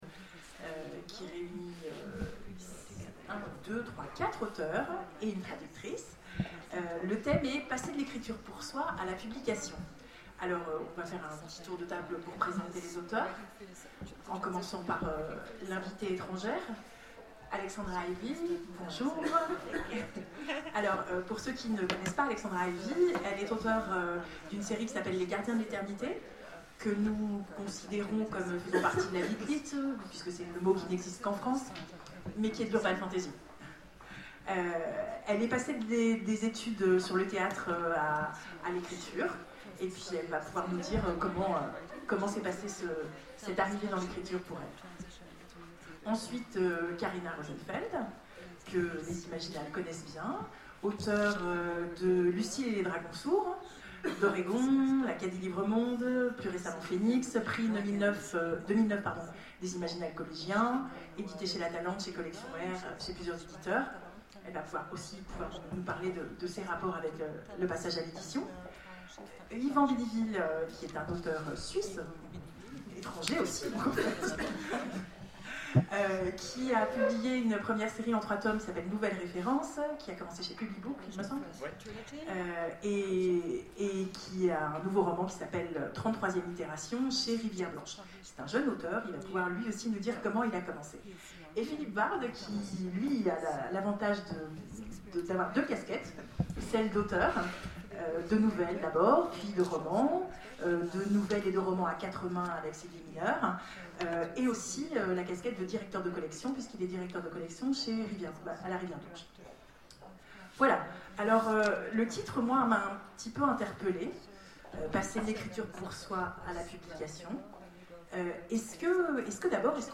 Imaginales 2013 : Conférence Ecrire...